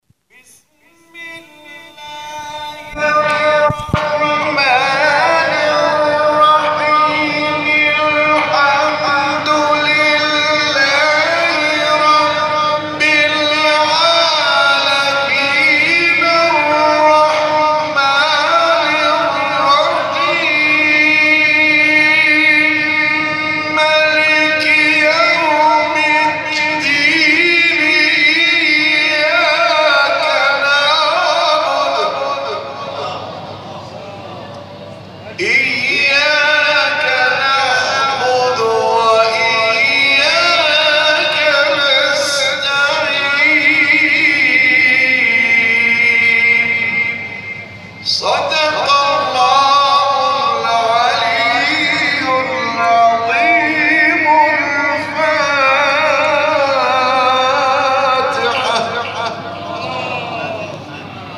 گروه فعالیت‌های قرآنی: محفل انس با قرآن کریم، شب گذشته، 25 خردادماه به مناسبت ماه مبارک رمضان در مسجد شهدای تهران برگزار شد.
تلاوت سوره حمد